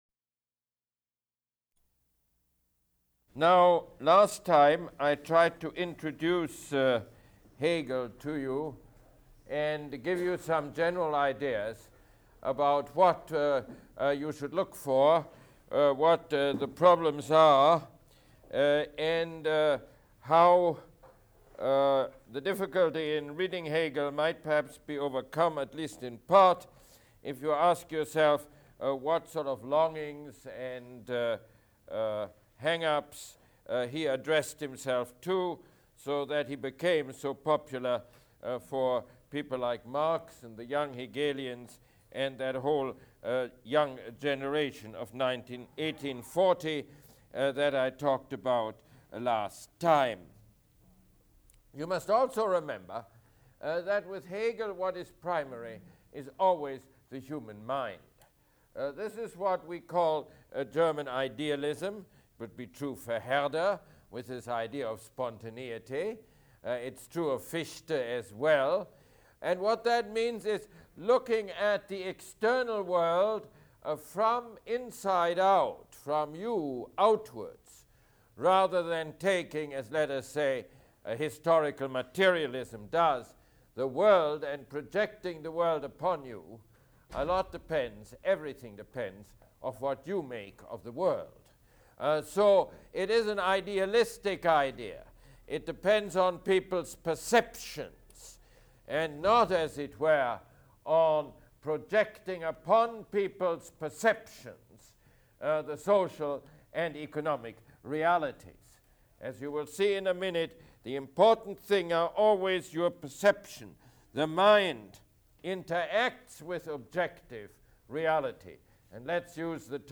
Mosse Lecture #20